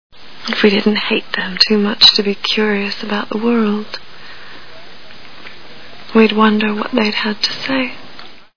Deadwood TV Show Sound Bites